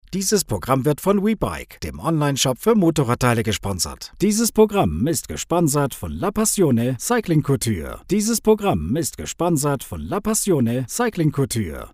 German voice over. Recording billboard